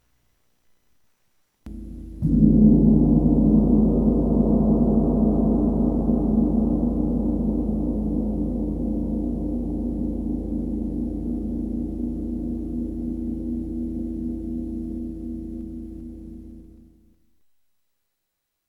Eveil-Corpus-Gong.mp3